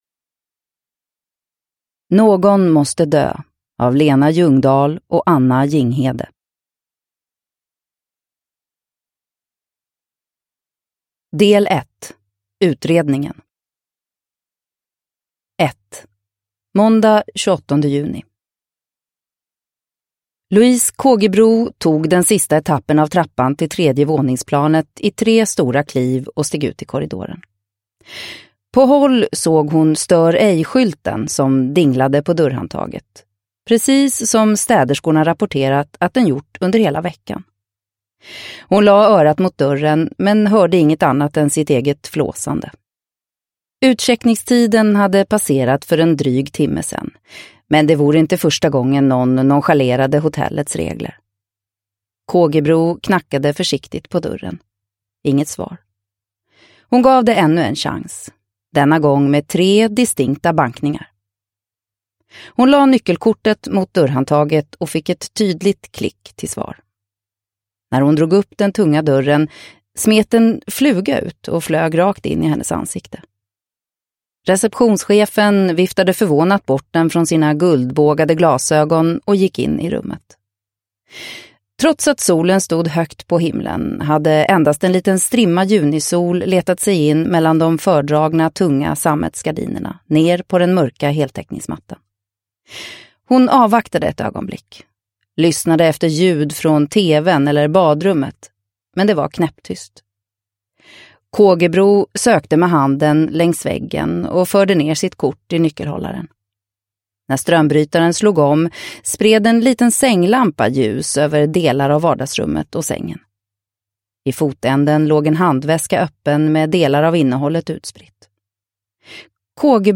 Någon måste dö – Ljudbok – Laddas ner